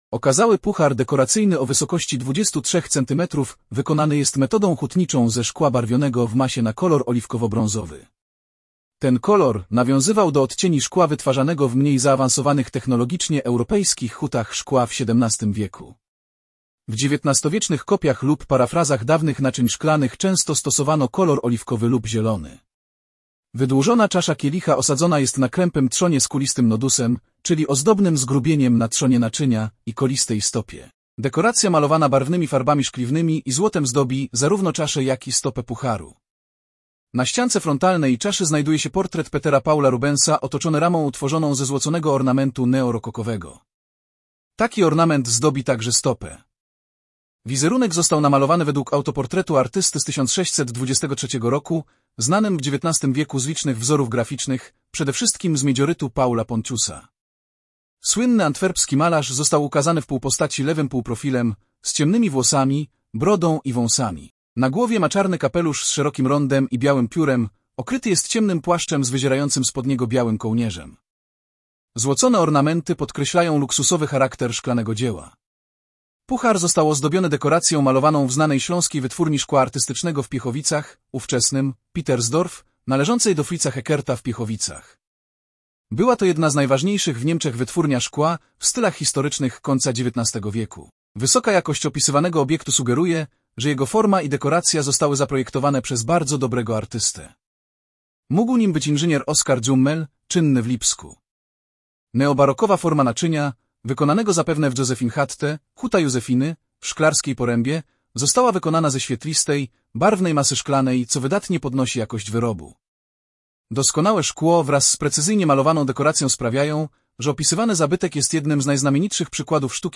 MNWr_audiodeskr_Puchar_z_Rubensem_MNWr.mp3